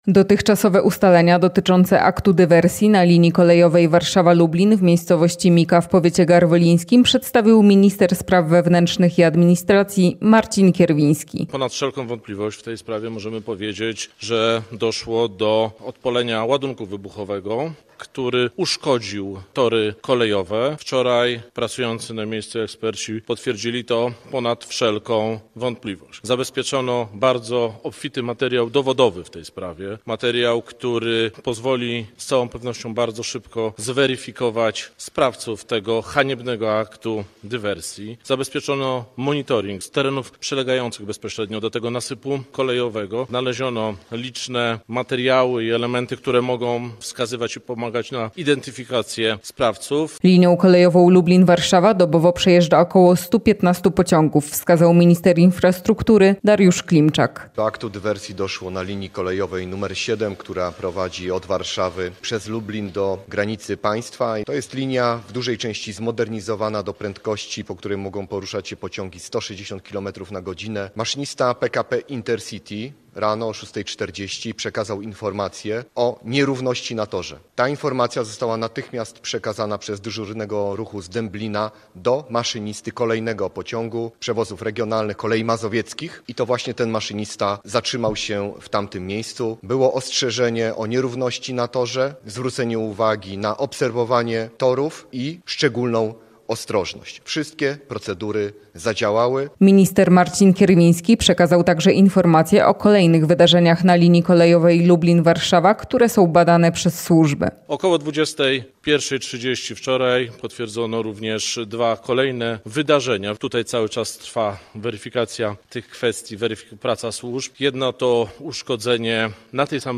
W sprawie zabezpieczony został bogaty materiał dowodowy, a informacje zgromadzone do tej pory przez służby zostały przedstawione podczas popołudniowej konferencji prasowej ministrów Marcina Kierwińskiego, Tomasza Siemoniaka, Waldemara Żurka, Dariusza Klimczaka.